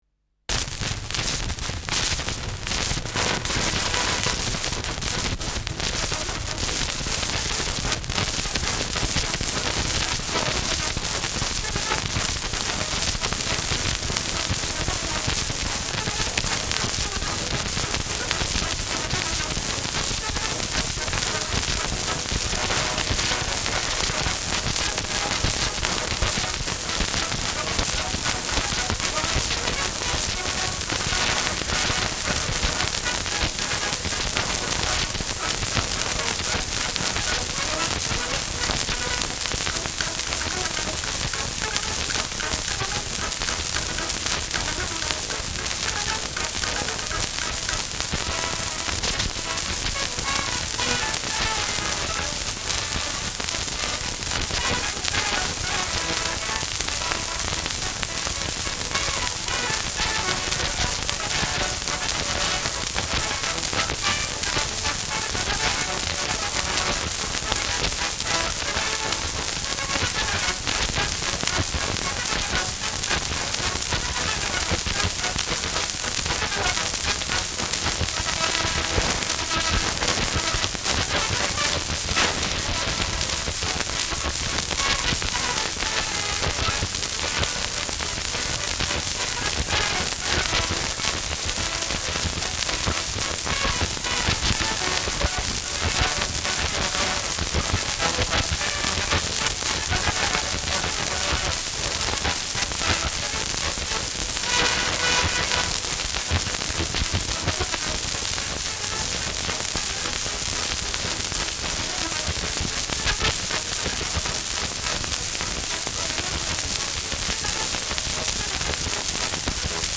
Schellackplatte
[unbekanntes Ensemble] (Interpretation)
[New York] (Aufnahmeort)